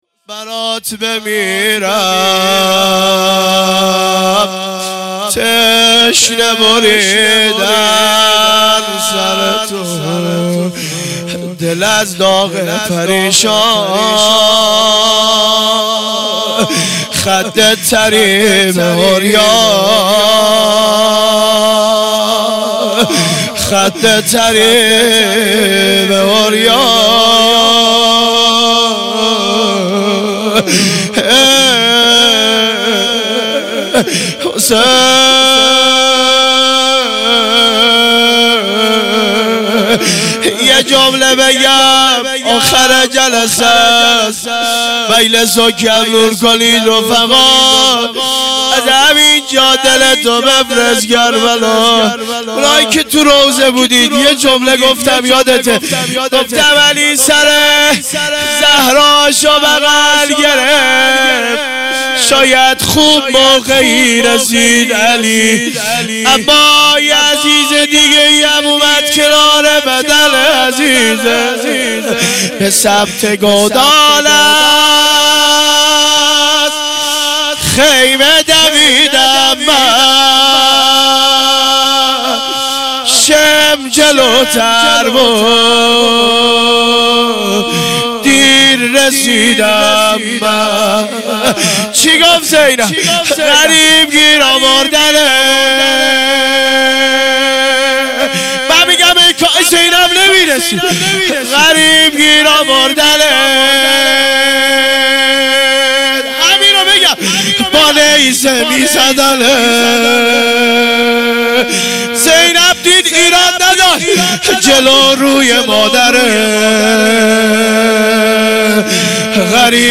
هیئت دانش آموزی انصارالمهدی(عج)-دارالعباده یزد